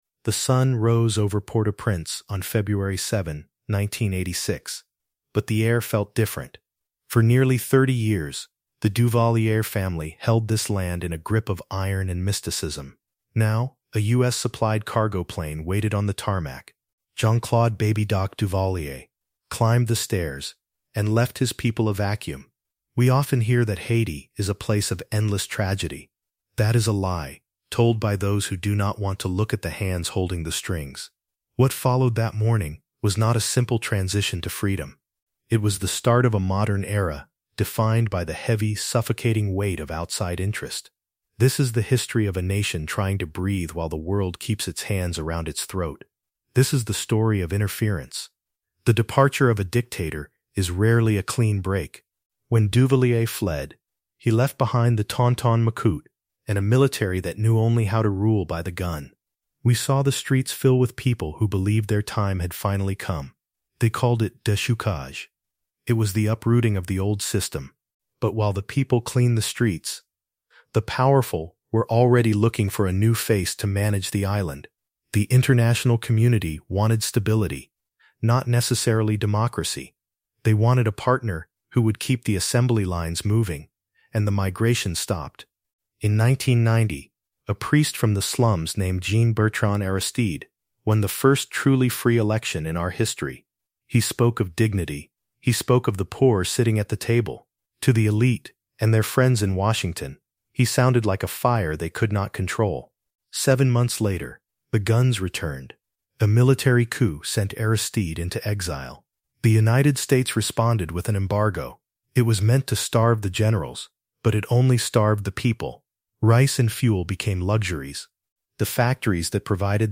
This long-form documentary explores the modern history of Haiti and the cyclical nature of foreign interference from the fall of the Duvalier dictatorship in nineteen eighty-six to the current gang crisis of twenty twenty-six.